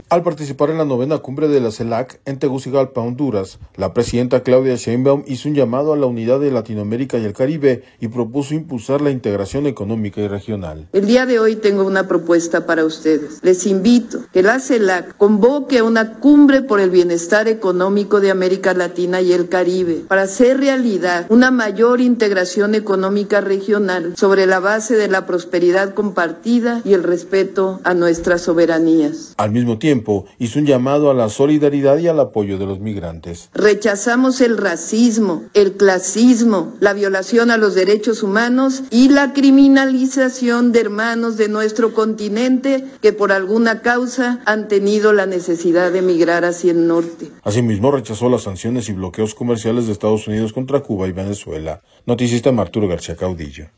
Al participar en la novena Cumbre de la CELAC, en Tegucigalpa, Honduras, la presidenta Claudia Sheinbaum hizo un llamado a la unidad de Latinoamérica y el Caribe y propuso impulsar la integración económica regional.